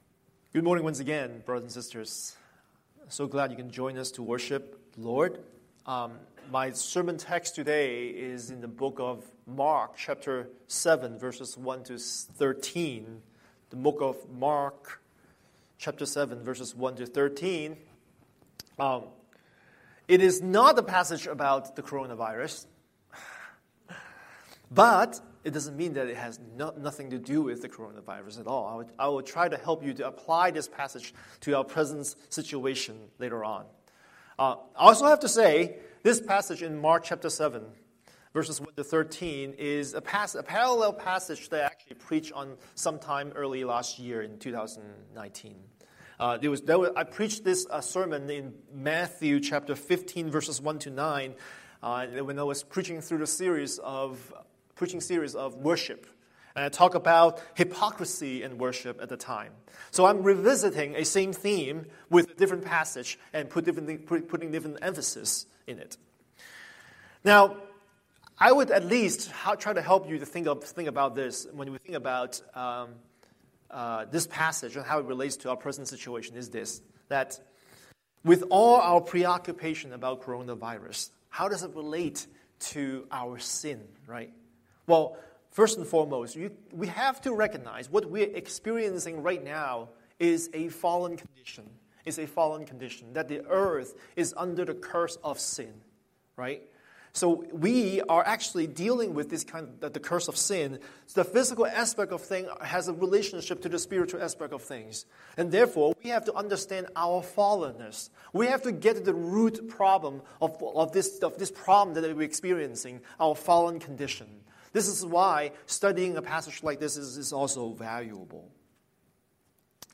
Scripture: Mark 7:1-13 Series: Sunday Sermon